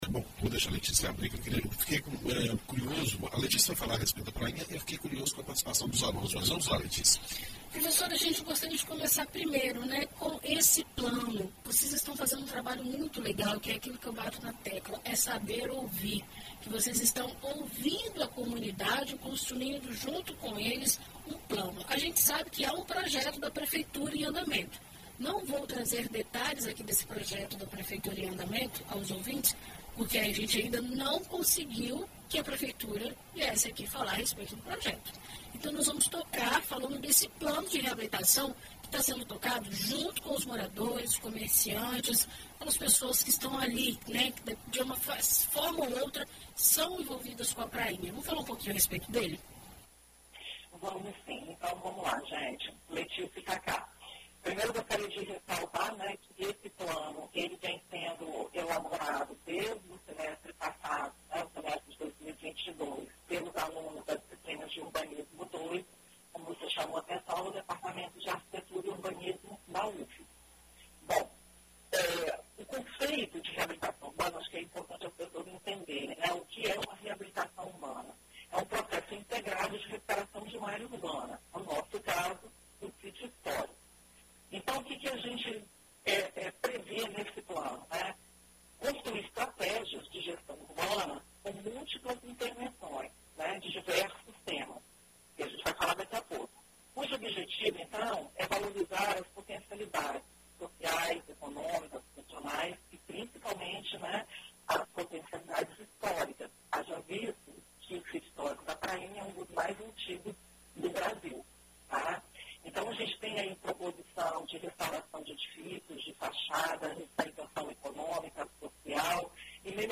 Em entrevista a BandNews FM ES